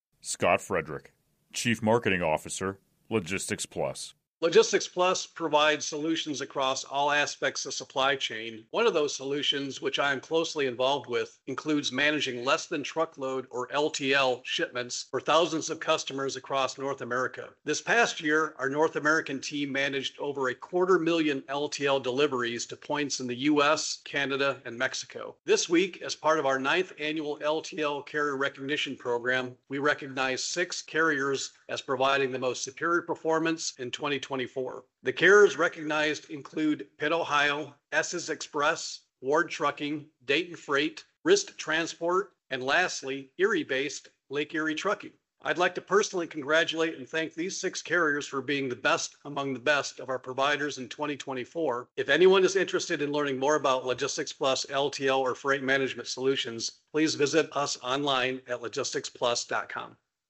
Brief Audio Comments